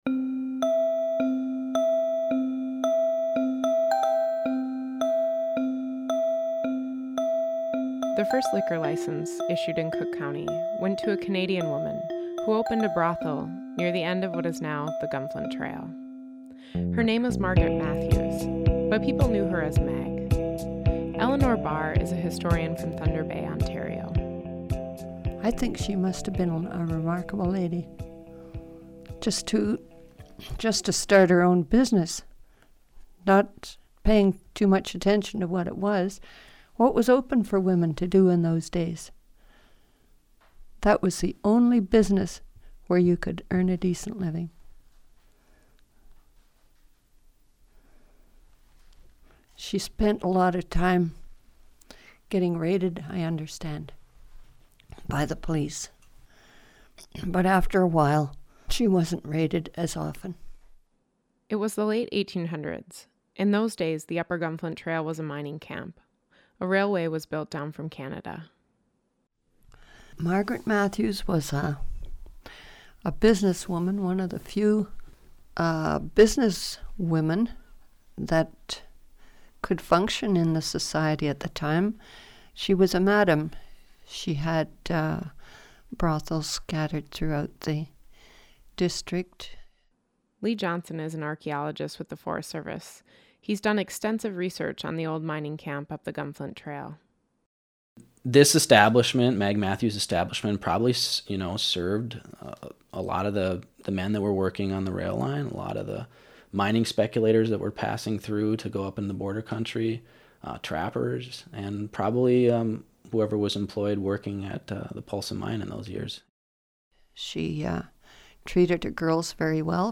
In Moments in Time, we speak with community members about their memories from different periods of our region's past to help foster an appreciation and understanding of the community in which we find ourselves today.